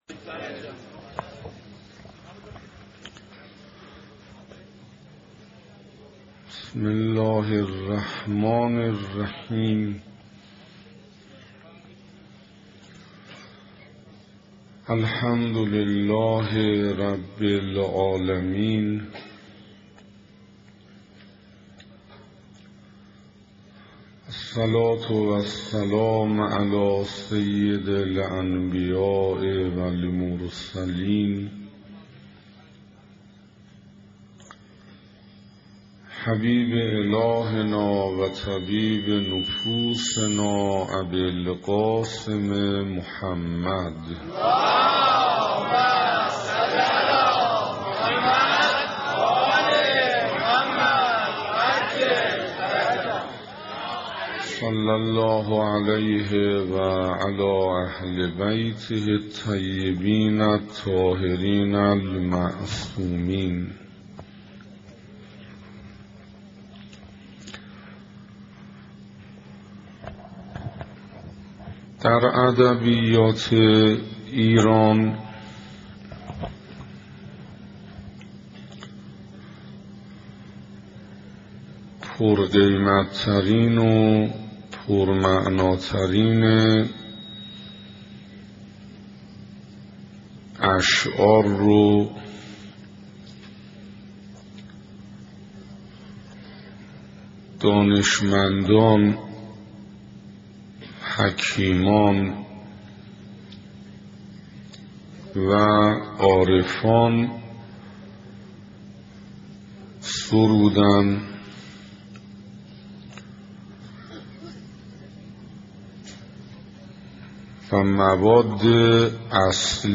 سخنراني چهارم
صفحه اصلی فهرست سخنرانی ها ارزيابي ارزش انسان (2) سخنراني چهارم (تهران حسینیه صاحب‌الزمان(عج)) محرم1428 ه.ق - بهمن1385 ه.ش دانلود متاسفم..